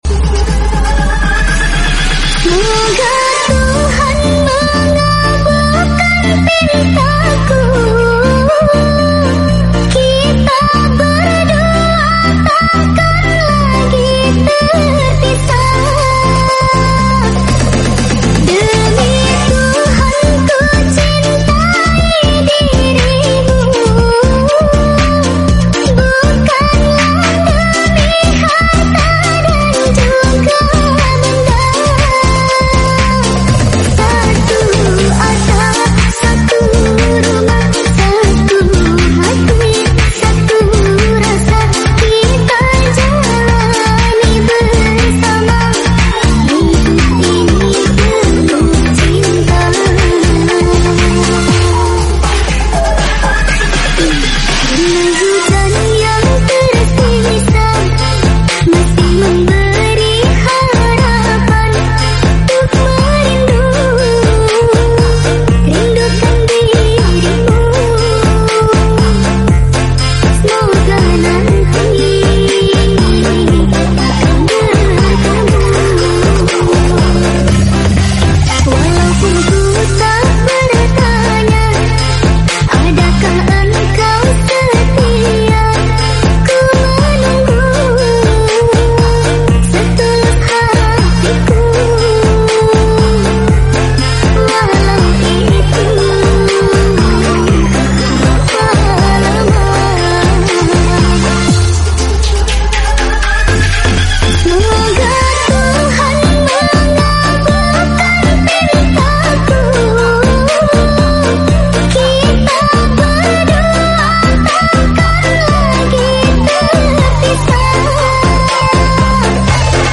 Musik DJ